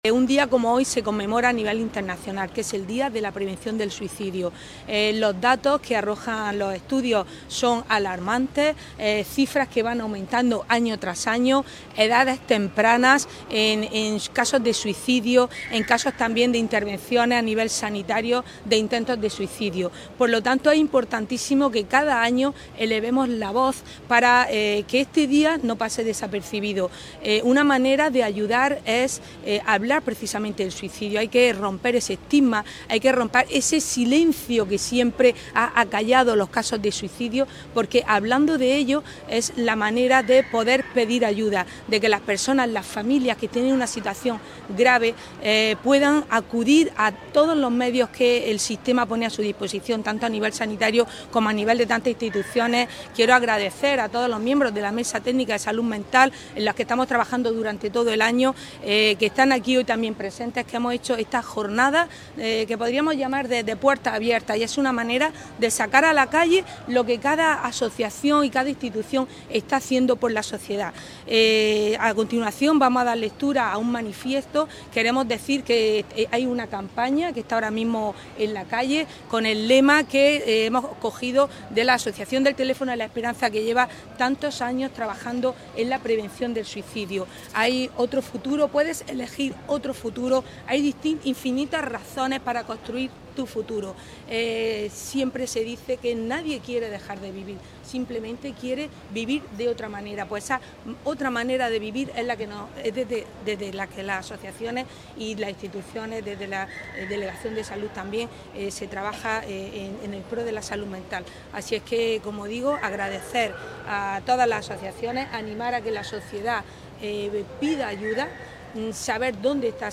La concejala Paola Laynez asiste a la lectura del Manifiesto, dentro de los actos organizados por la Mesa Técnica de Salud Mental
Durante el acto, la concejala de Familia, Inclusión e Igualdad, Paola Laynez, ha destacado que “hablar sobre esta problemática es una forma de prevención, de romper el estigma y de animar a las personas a pedir ayuda a las asociaciones y recursos disponibles”.